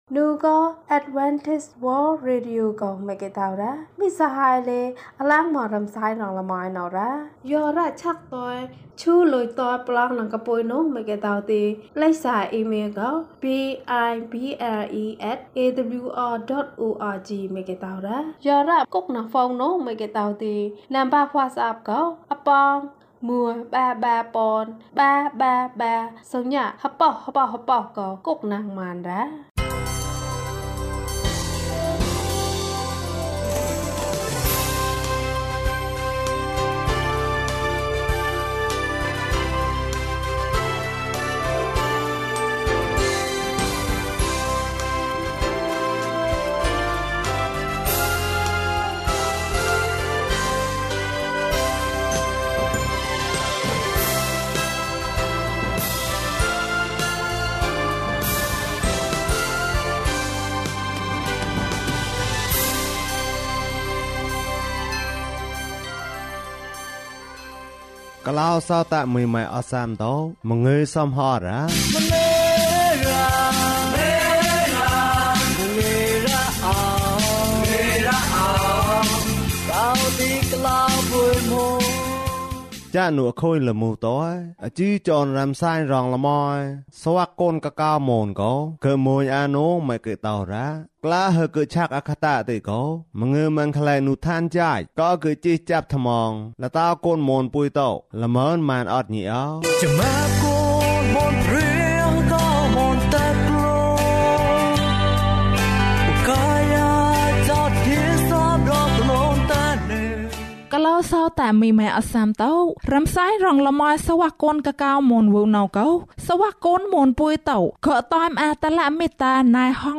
ခရစ်တော်ကို ယုံကြည်ပါ။ ၀၂ ကျန်းမာခြင်းအကြောင်းအရာ။ ဓမ္မသီချင်း။ တရား‌ဒေသနာ။